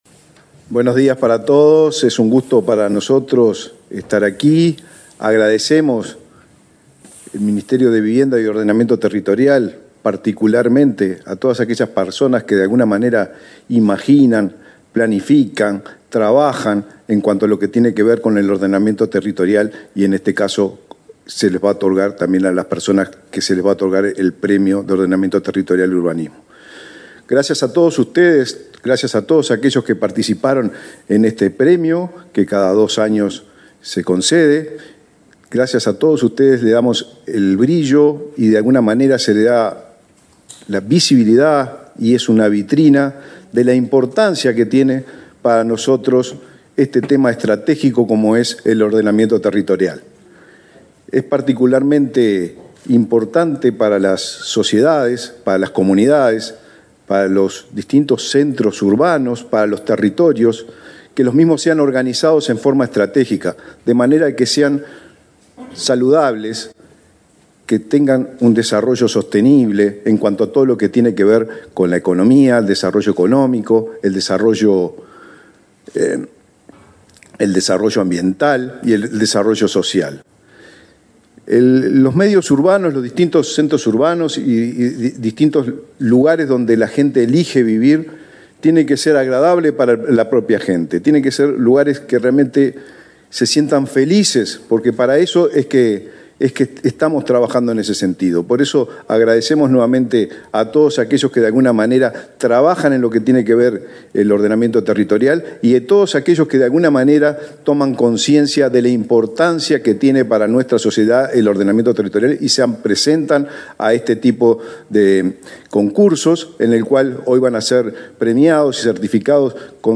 Palabra de autoridades del Ministerio de Vivienda y Ordenamiento Territorial
Palabra de autoridades del Ministerio de Vivienda y Ordenamiento Territorial 08/11/2023 Compartir Facebook X Copiar enlace WhatsApp LinkedIn El ministro de Vivienda y Ordenamiento Territorial, Raúl Lozano, y el director de Ordenamiento Territorial de esa cartera, José Pedro Aranco, participaron, este miércoles 8 en Montevideo, del acto de entrega del Premio Nacional de Ordenamiento Territorial y Urbanismo 2023.